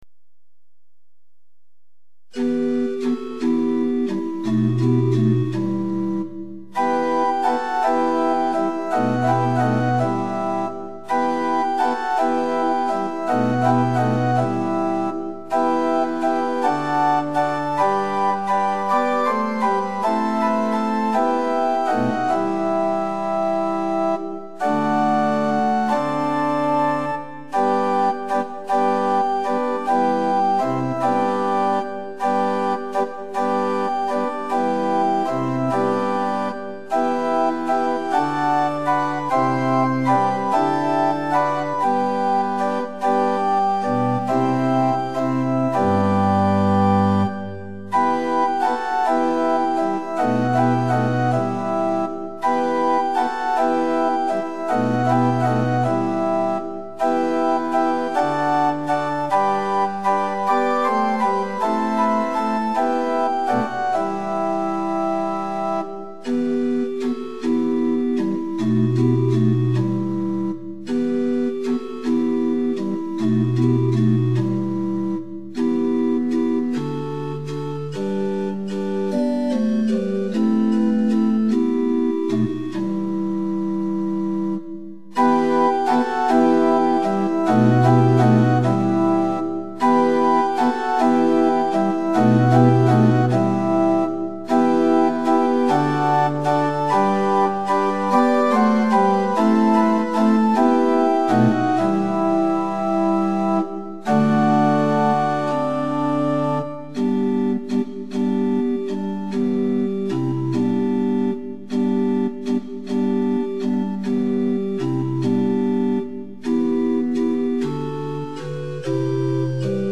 ♪賛美用オルガン伴奏音源：
・柔らかい音色(ロア・フルート8')部分は前奏です
・はっきりした音色(プリンシパル8'+4')になったら歌い始めます
・節により音色が変わる場合があります
・間奏は含まれていません
Tonality = e
Pitch = 440
Temperament = Equal